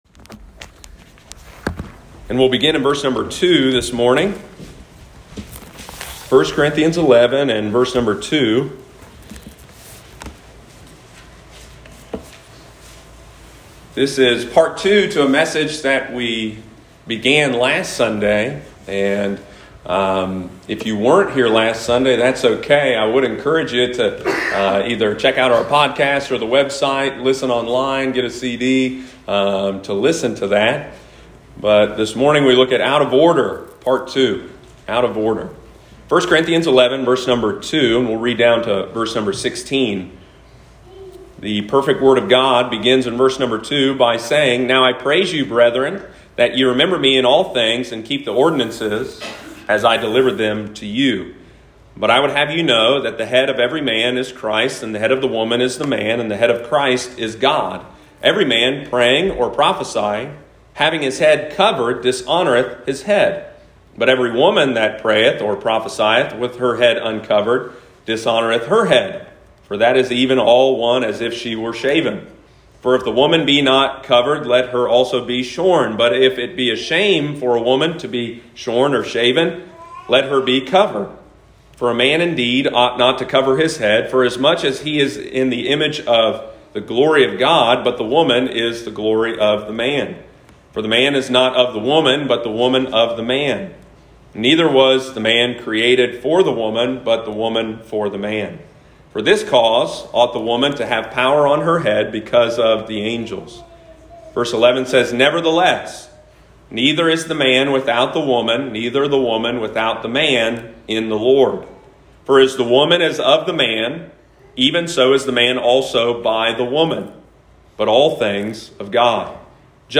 Sunday morning, July 11, 2021.